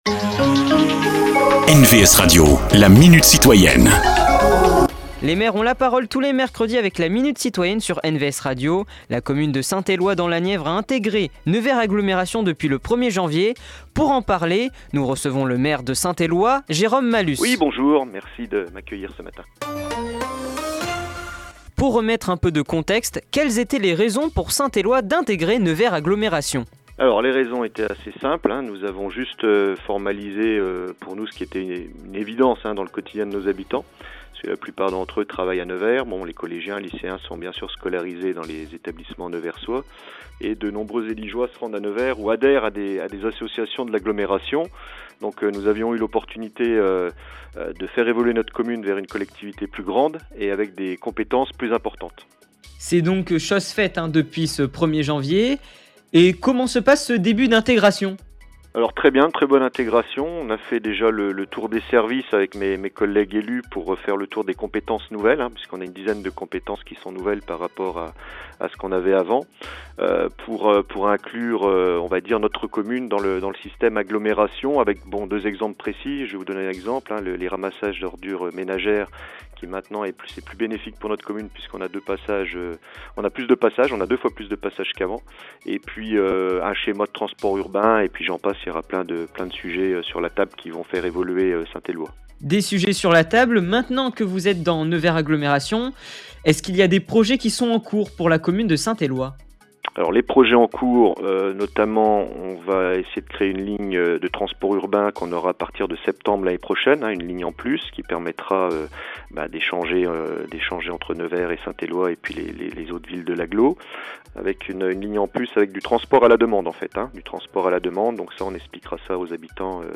Rencontre avec ceux qui font l’actualité du territoire.
Cette semaine Jérome Malus, maire de Saint-Éloi dans la Nièvre.